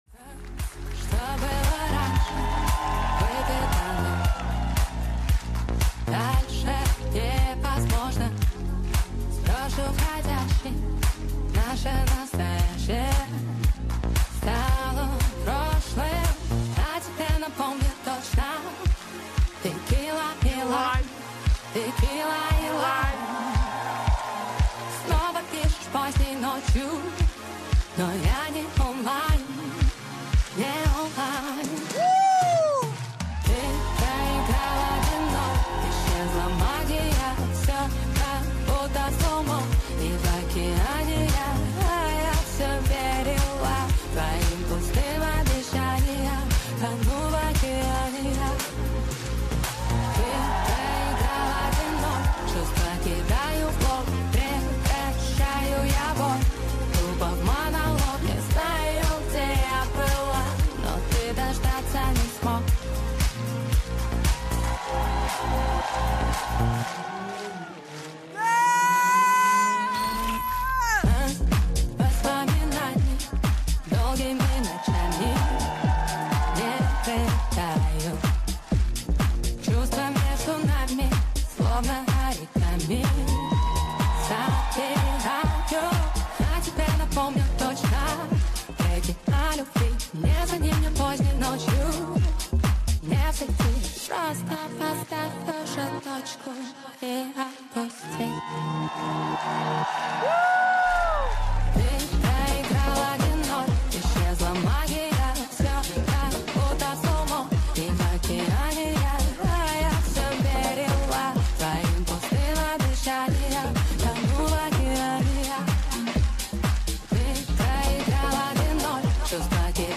• Жанр музыки: Русская Поп-музыка